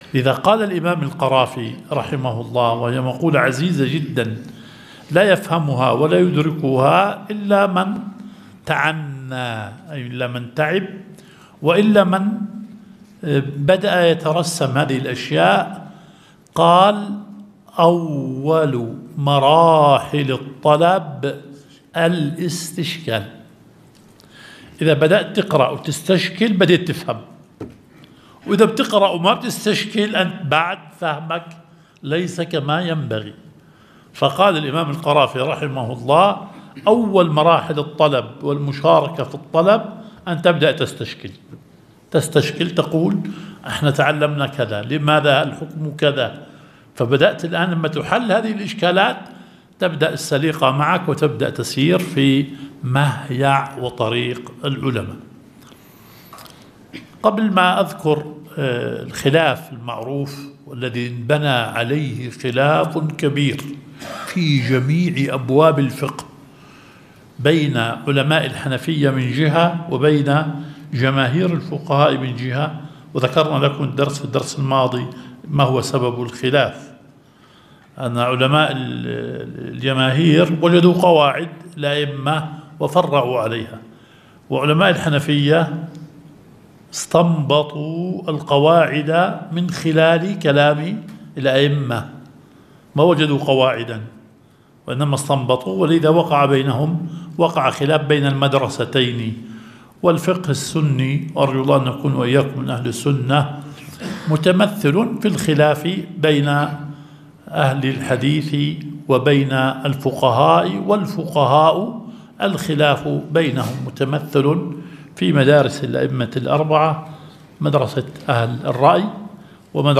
درس ١٣ – مبحث العام والخاص